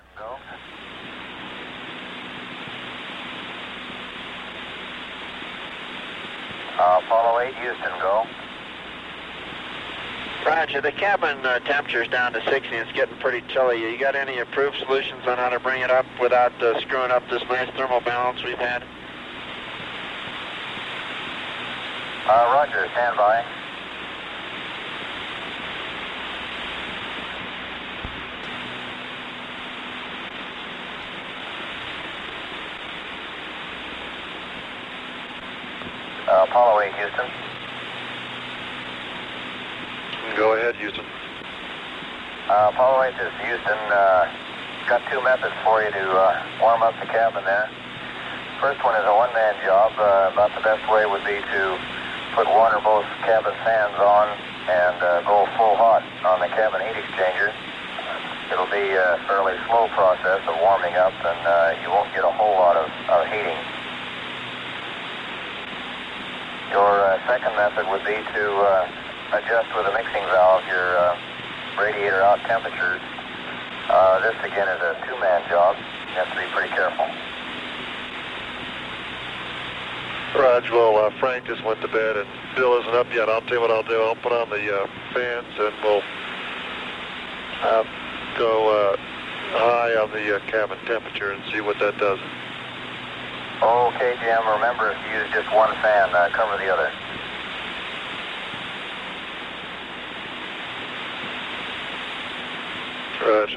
Audio recorded at Honeysuckle Creek